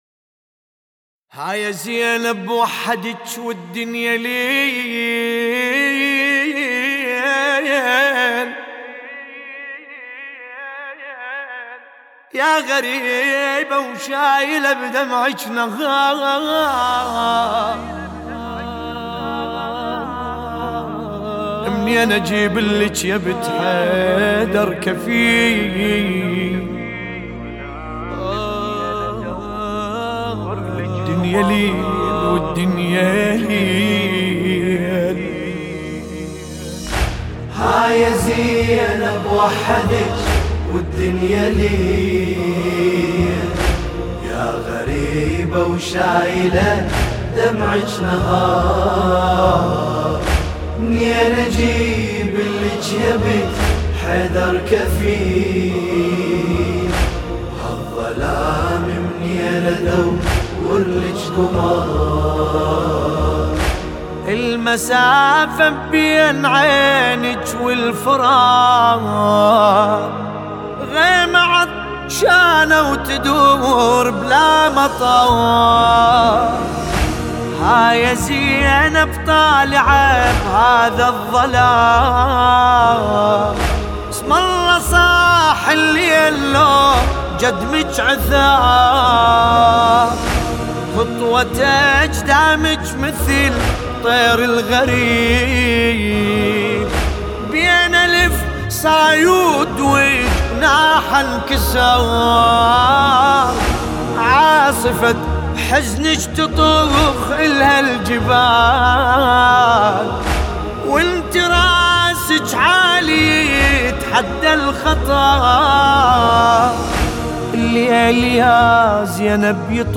سينه زنی